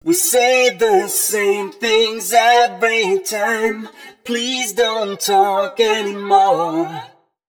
028 male.wav